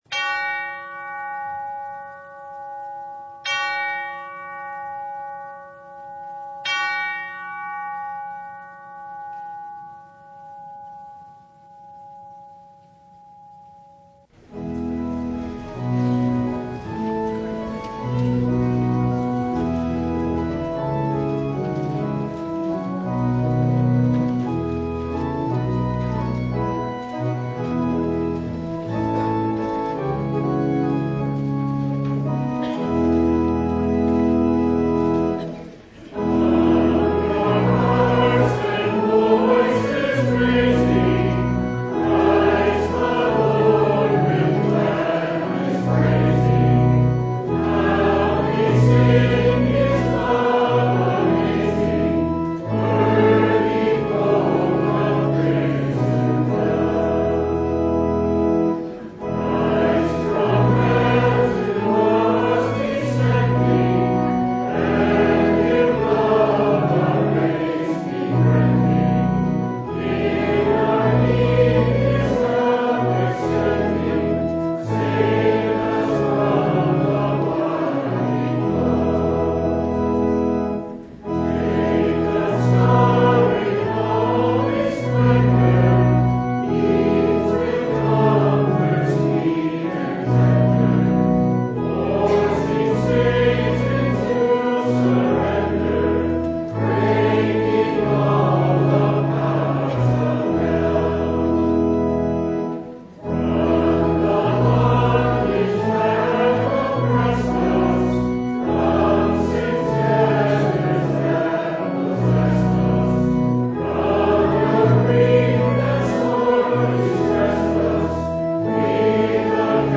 Christmas Eve Vespers (2022)
Passage: Isaiah 9:2-7; Luke 2:1-16 Service Type: Christmas Eve Vespers
Topics: Full Service